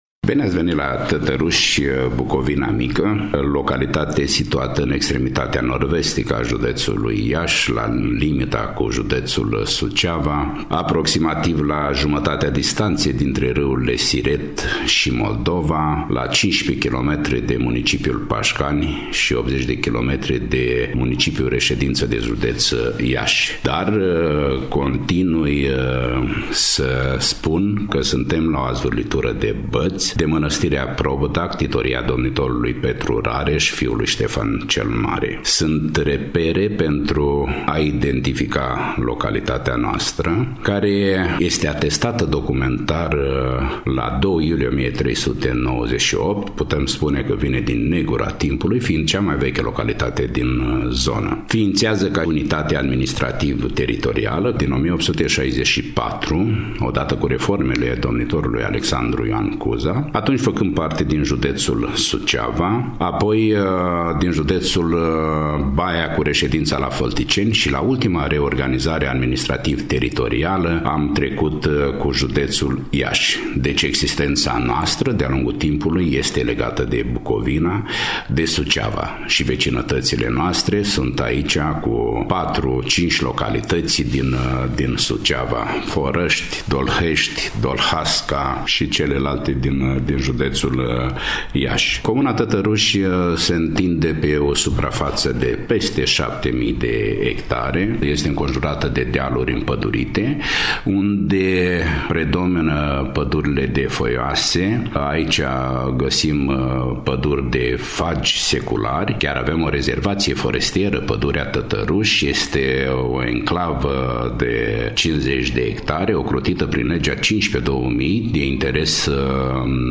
Primul invitat la dialog este primarul comunei – Costel Iosub – cel care ne introduce în atmosfera zonei, prezentându-ne localitățile pe care le administrează.